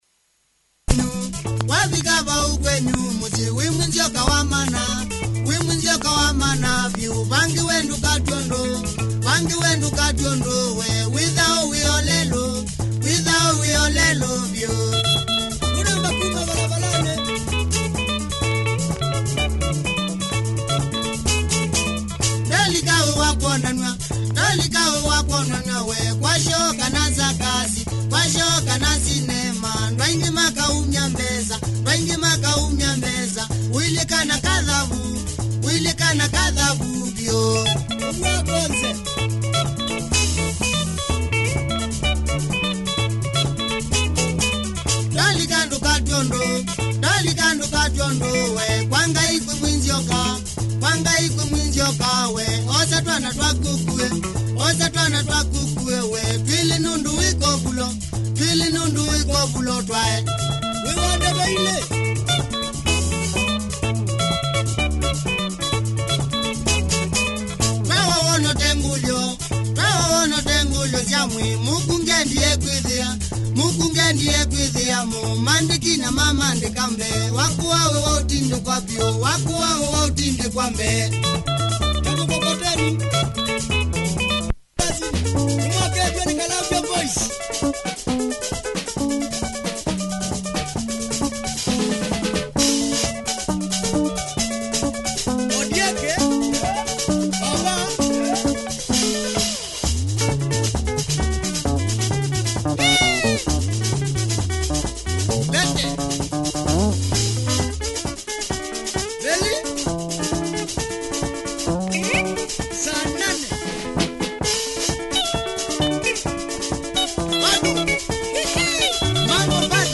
Killer kikamba benga https